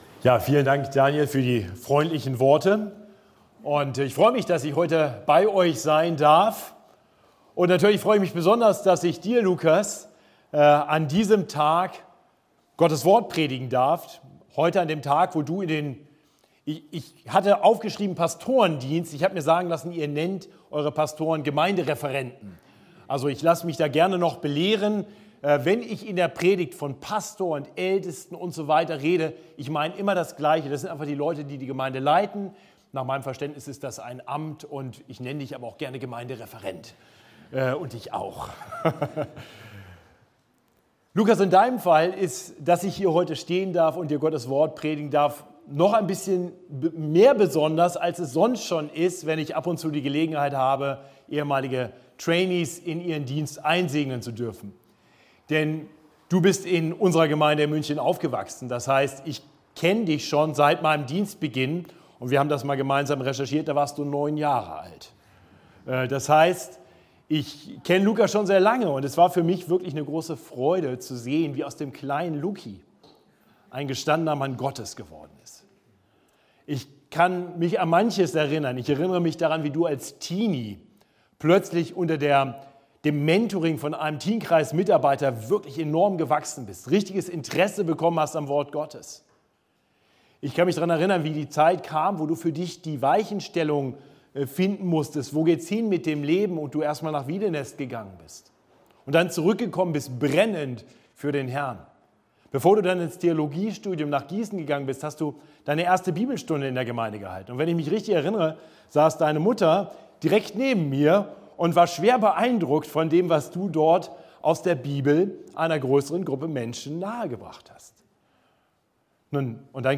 Einführungsgottesdienst